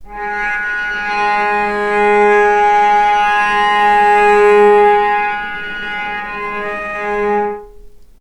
healing-soundscapes/Sound Banks/HSS_OP_Pack/Strings/cello/sul-ponticello/vc_sp-G#3-mf.AIF at 01ef1558cb71fd5ac0c09b723e26d76a8e1b755c
vc_sp-G#3-mf.AIF